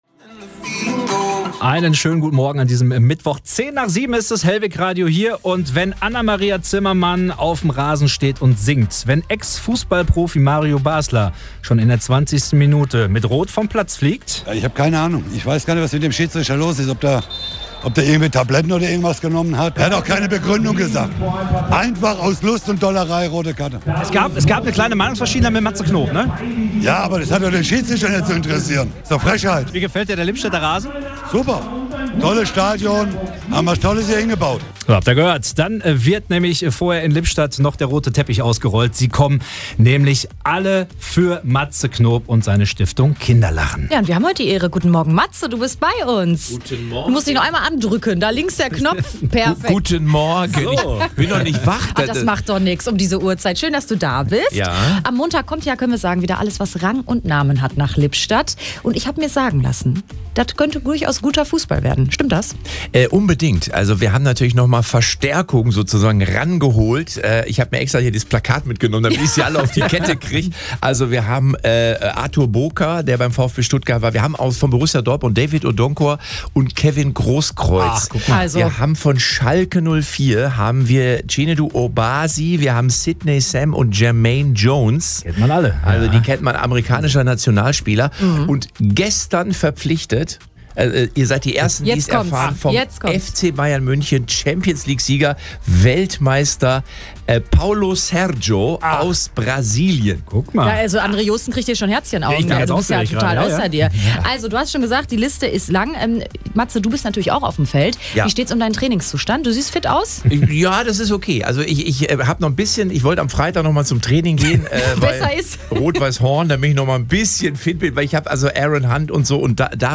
matze_knop_in_der_sendung_mitschnitt_-_.mp3